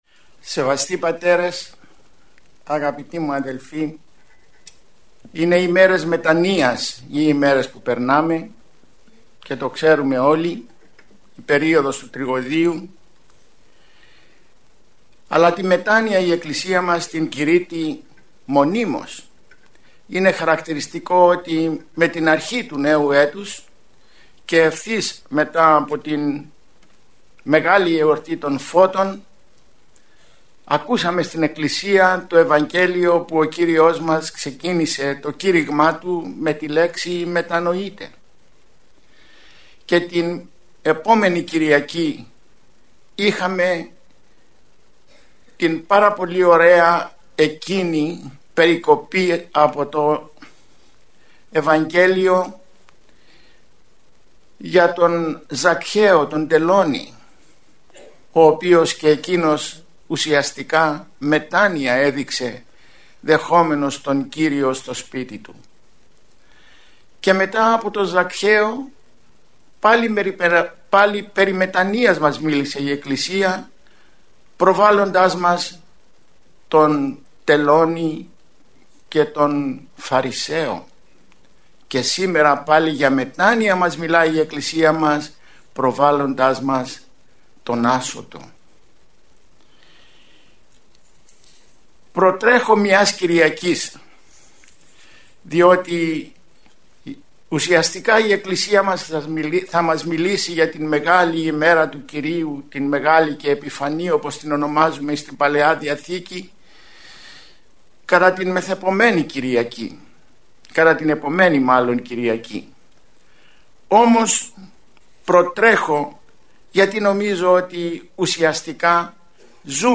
Η ομιλία αυτή πραγματοποιήθηκε στην αίθουσα της Χριστιανικής Ενώσεως Αγρινίου.
ομιλία